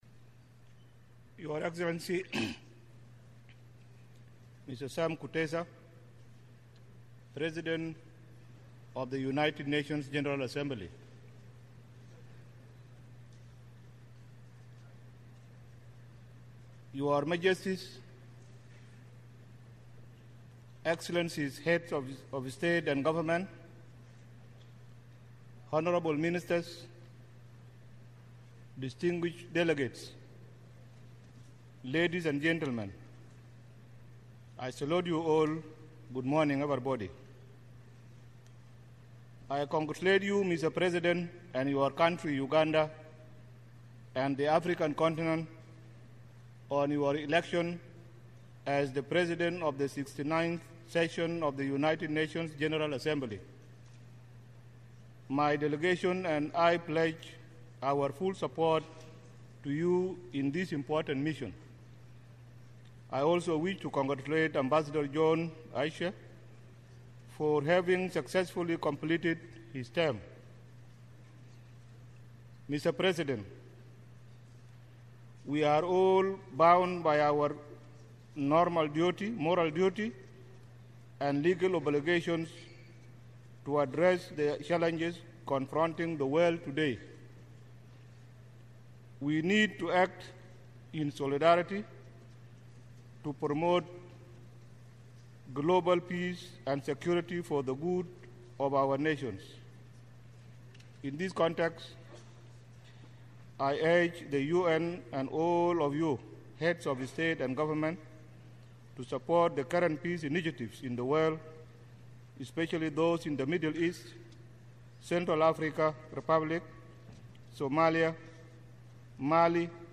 President Salva Kiir's speech to the U.N. General Assembly, Sept. 27, 2014.